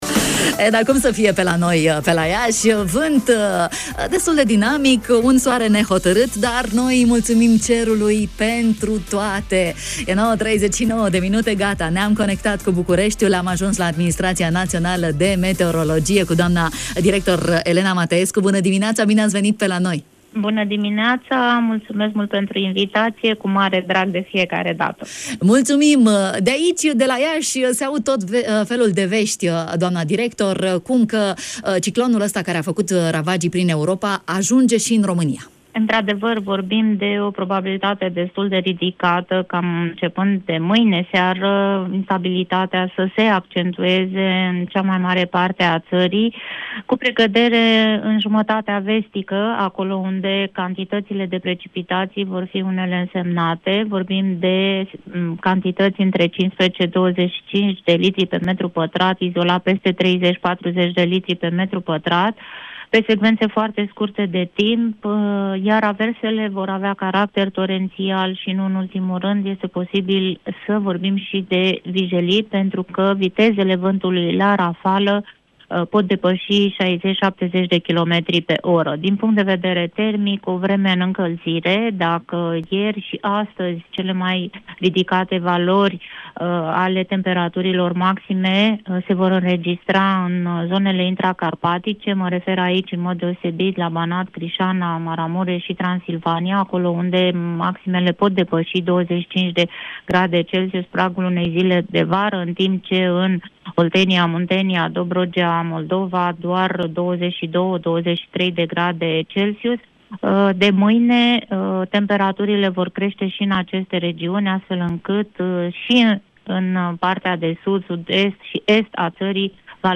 Directorul Administrației Naționale a Meteorologiei, Elena Mateescu, ne-a dat veștile, cu hărțile în față: